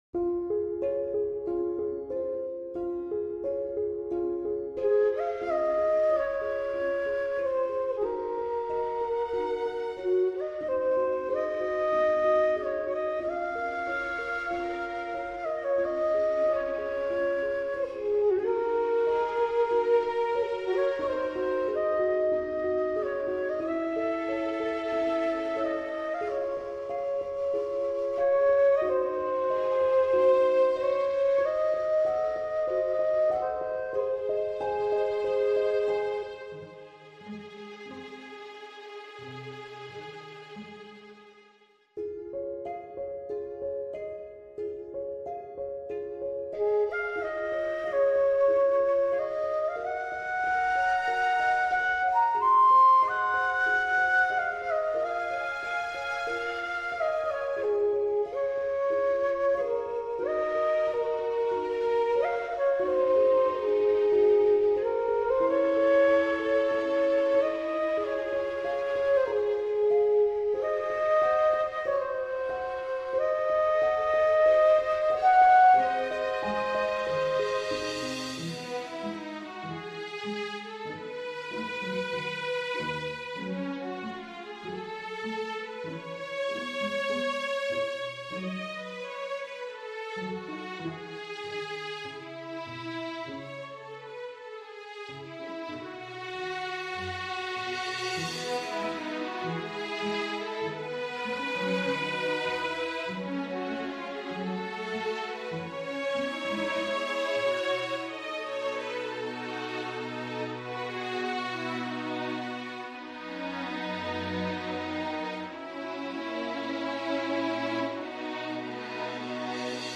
heartwarming lullaby